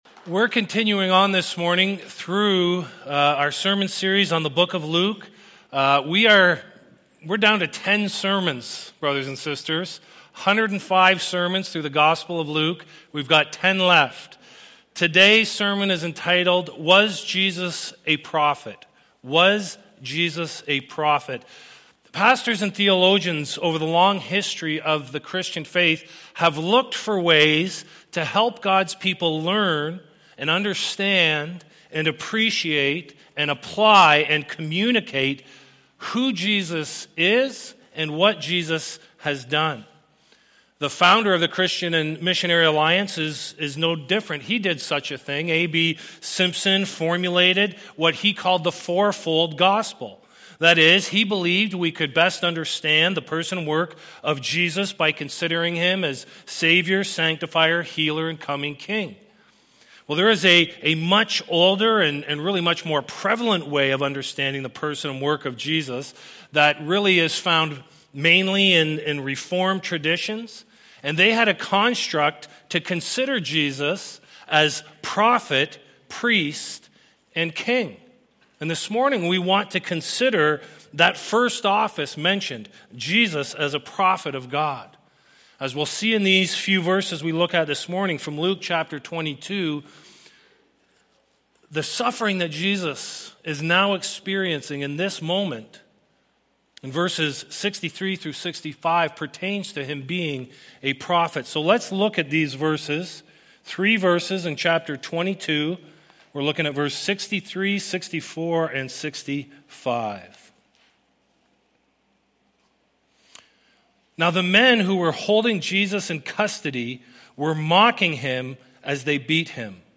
Sermon Archives Luke 22:63-65 - Was Jesus A Prophet? This week we answer the question, “Was Jesus a prophet?’